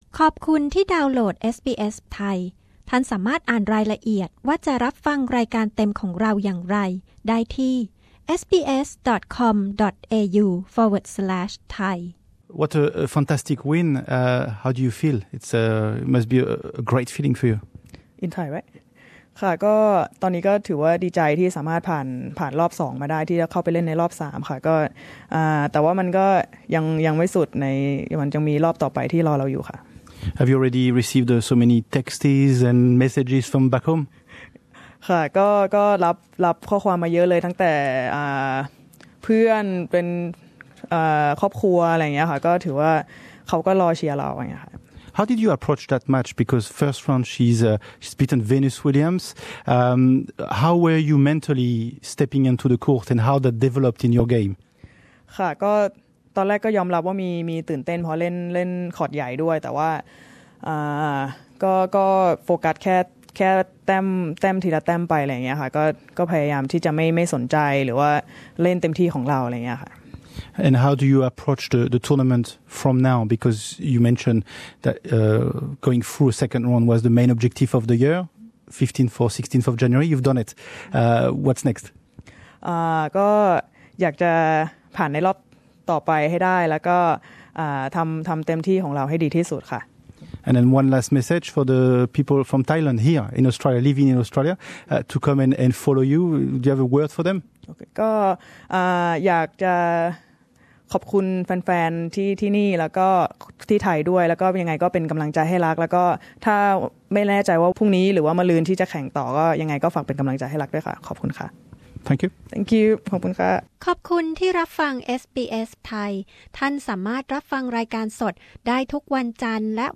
Exclusive interview with Luksika Kumkhum, Thai female tennis player going through to the 3rd of the Australian Open for the first time in her career.